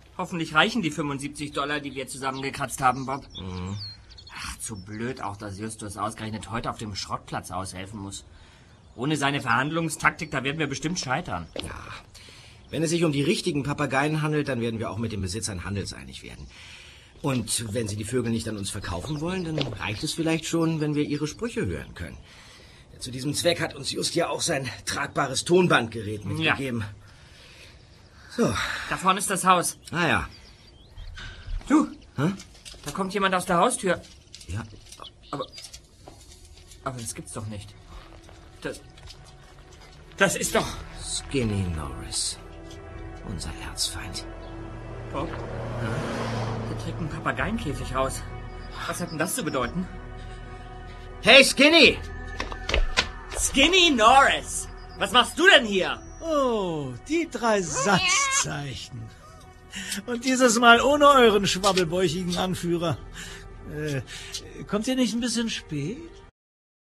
- Super-Papagei 2004 | Physical CD Audio drama